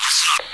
radio_listenup.wav